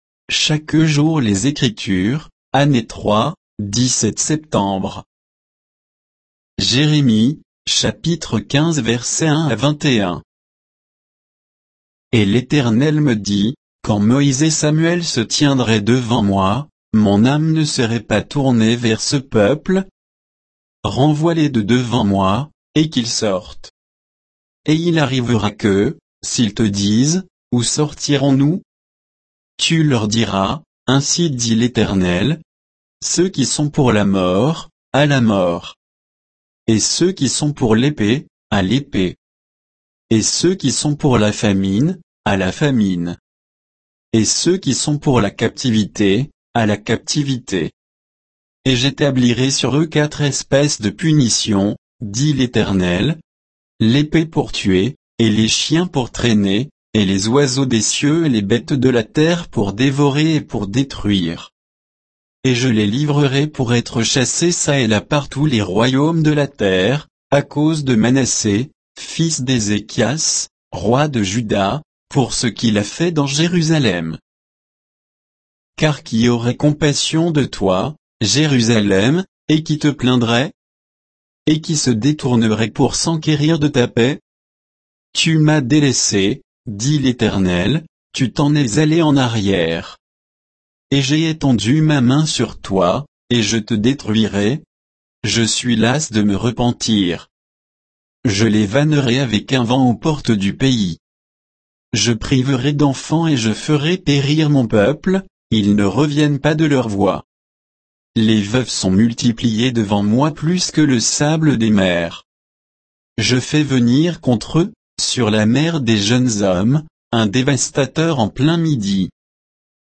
Méditation quoditienne de Chaque jour les Écritures sur Jérémie 15